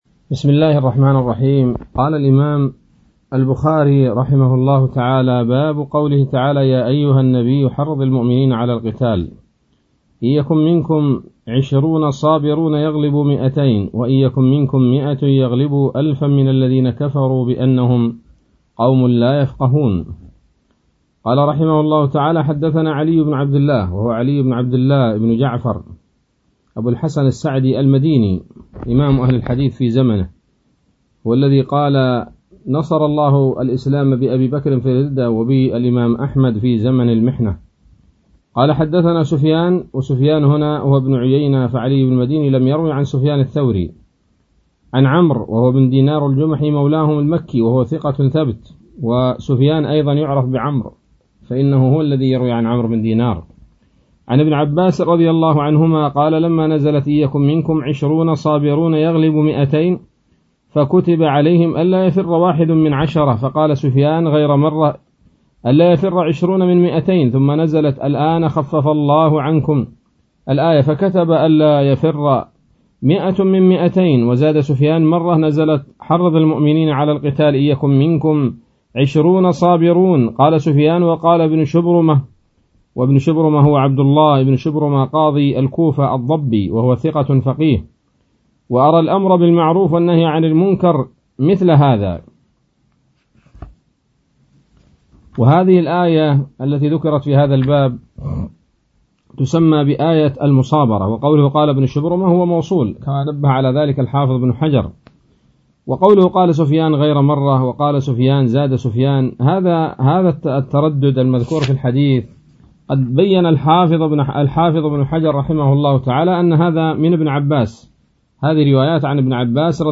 الدرس السادس عشر بعد المائة من كتاب التفسير من صحيح الإمام البخاري